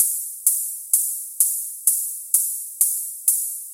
描述：埃德蒙，也许，很适合电子合成器
Tag: 128 bpm Electro Loops Pad Loops 1.26 MB wav Key : A